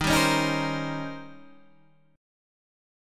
Eb13 chord